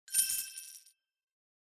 UI_SilverChange.mp3